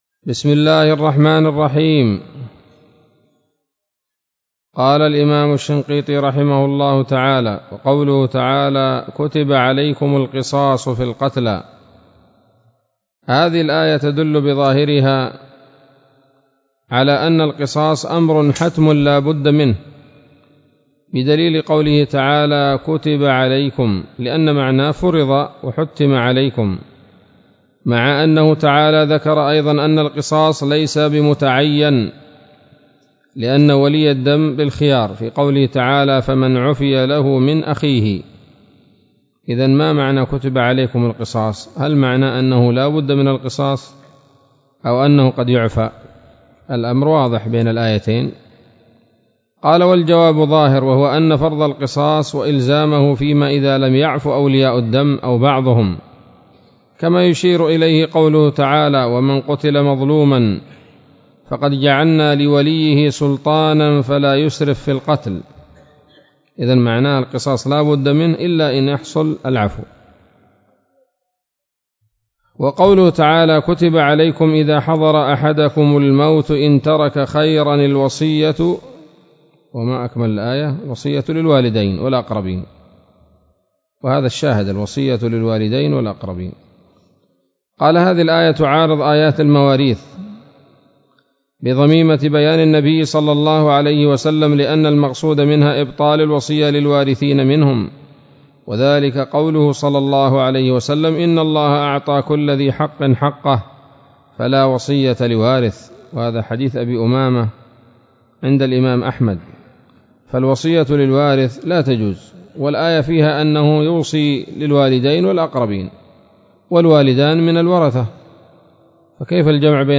الدرس السادس عشر من دفع إيهام الاضطراب عن آيات الكتاب